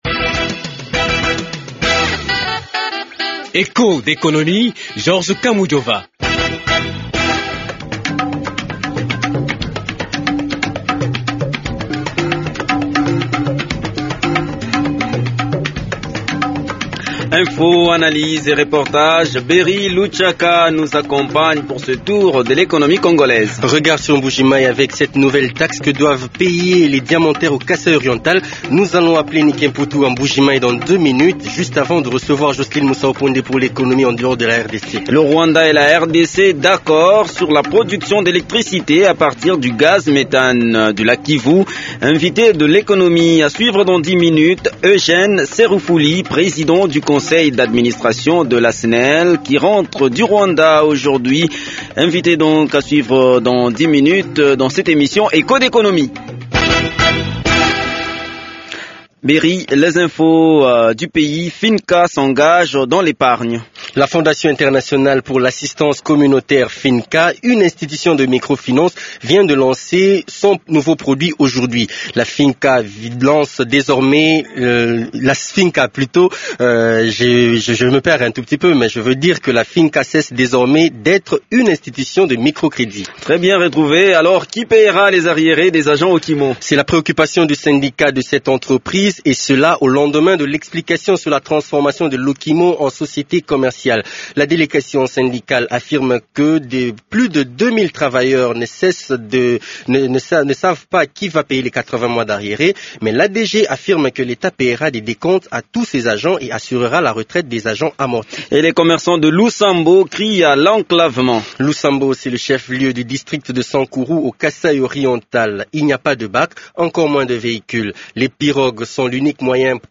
C’est l’une des conclusions de la réunion de deux jours entre responsables congolais et rwandais à Kigali. Eugene Serufuli, Président du Conseil d’administration de la SNEL qui y a pris part est l’invité de l’Economie. Dans cette émission Echos d’Economie, on aborde aussi la nouvelle taxe que doivent payer les diamantaires du Kasaï alors que le secteur tente de se relancer. Regard également sur Sandoa ou les droits de péage ne met pas tout le monde d’accord.